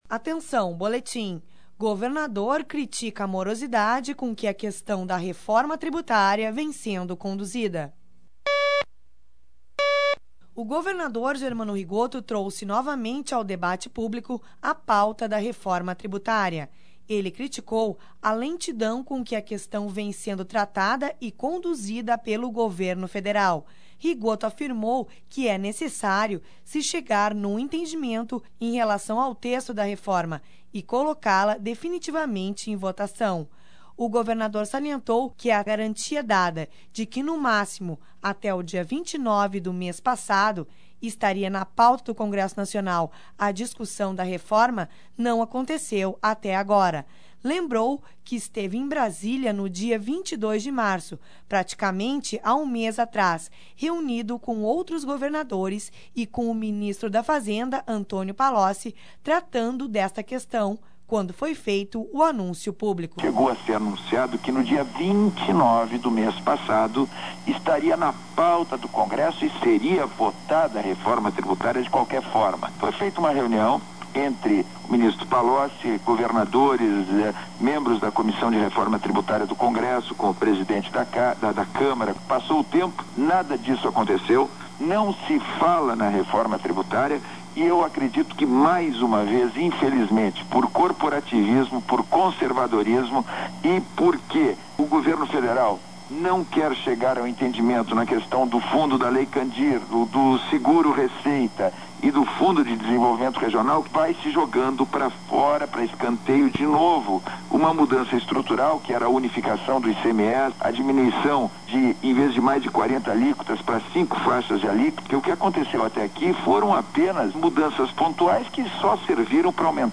O governador GERMANO RIGOTTO trouxe novamente ao debate público a questão da reforma tributária. Ele criticou a lentidão com que a questão da vem sendo tratada e conduzida pelo governo federal. (sonora: governador Germano Rigotto)Local: Porto Alegre -